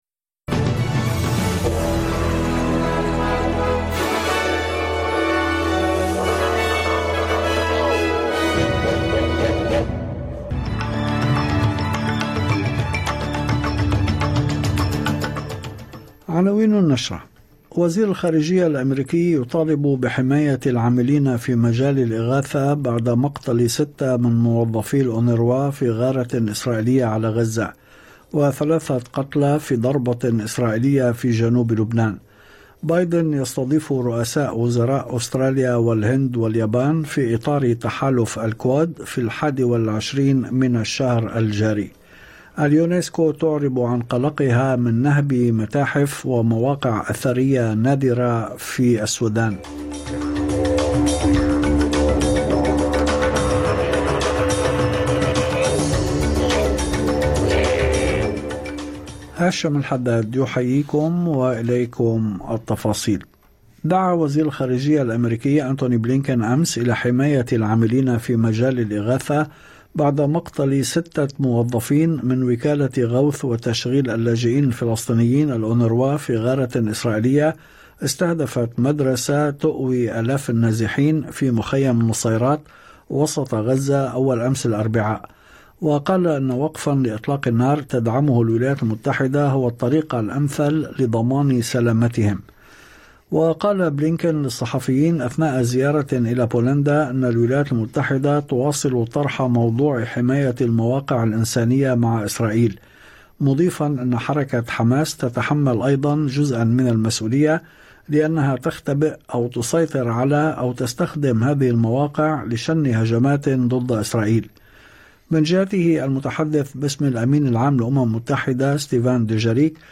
نشرة أخبار المساء 13/9/2024